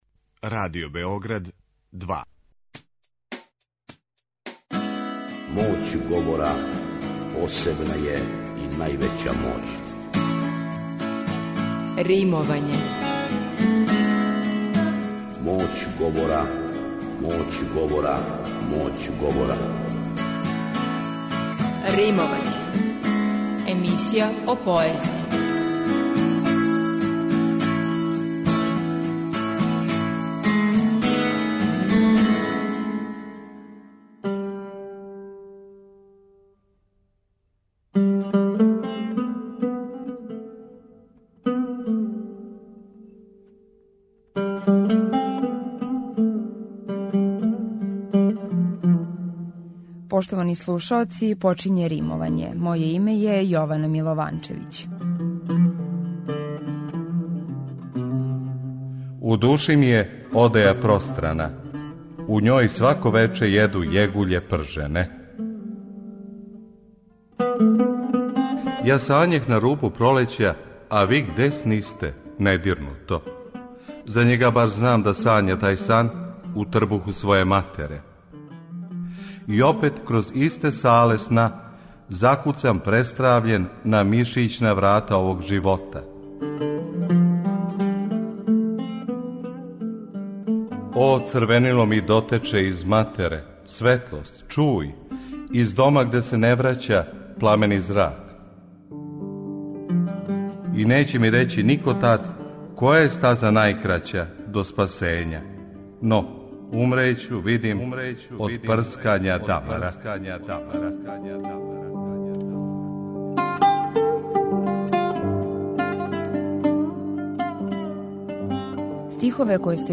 преузми : 10.78 MB Римовање Autor: Група аутора У новој емисији посвећеној поезији, слушаоци ће моћи да чују избор стихова из Звучног архива Радио Београда које говоре најчувенији домаћи и инострани песници, драмски уобличене поетске емисије из некадашње серије „Вртови поезије", као и савремено стваралаштво младих и песника средње генерације.